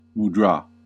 A mudra (/muˈdrɑː/
En-us-mudra-2.ogg.mp3